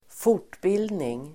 Ladda ner uttalet
Uttal: [²f'or_t:bil:dning]